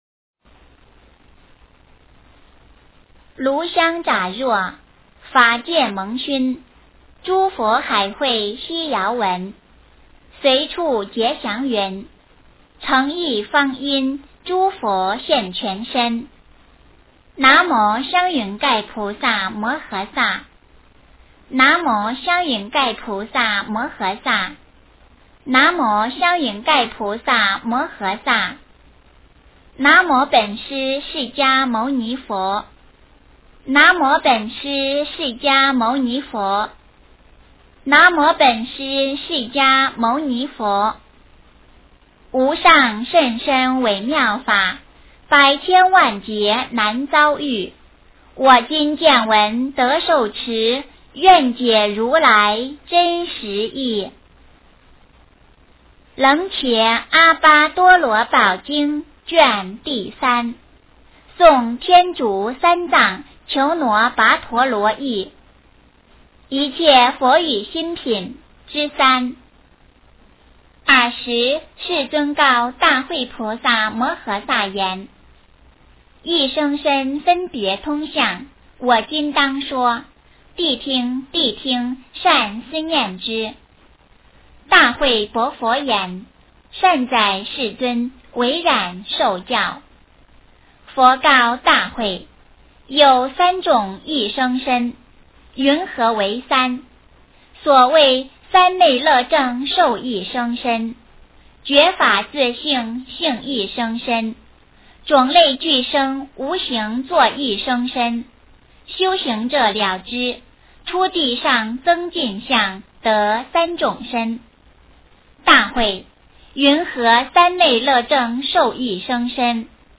楞伽阿跋多罗宝经3 - 诵经 - 云佛论坛